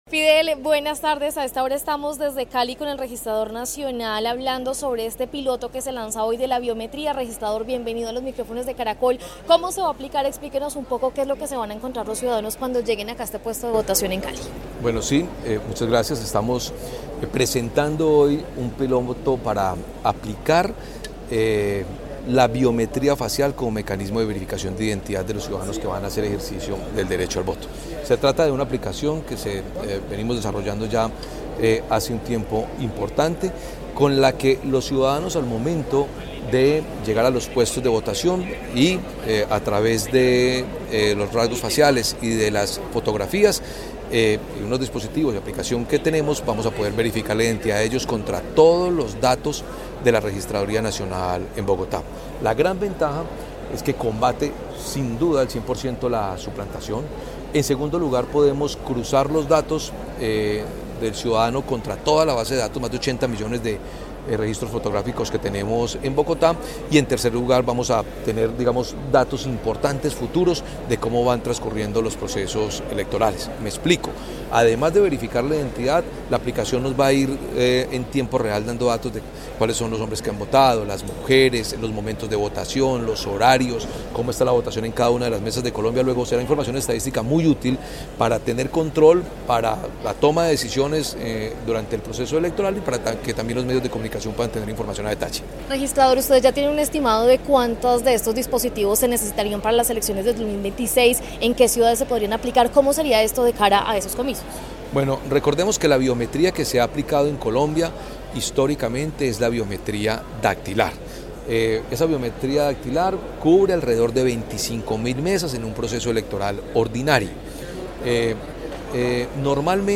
En diálogo con Caracol Radio Hernán Penagos, Registrador Nacional, explicó que esta nueva herramienta tecnológica busca fortalecer la transparencia y seguridad de los procesos electorales en el país.